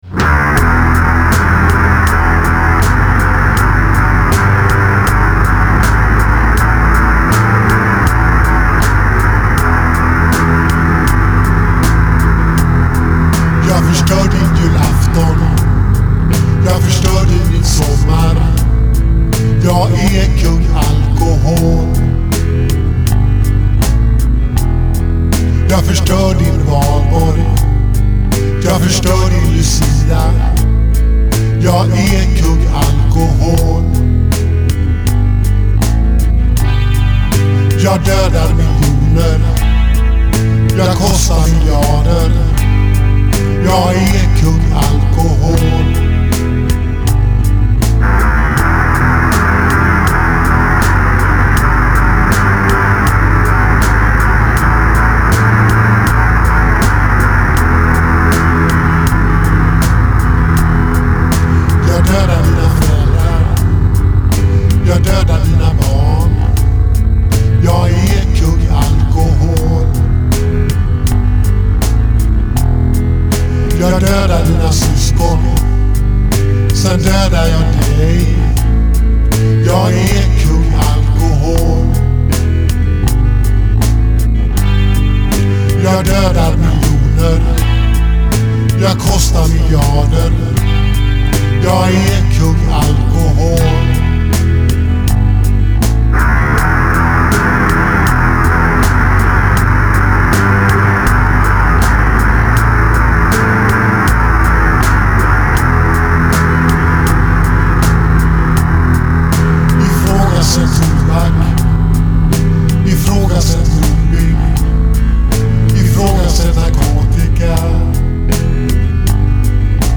E F# G G# A G E G A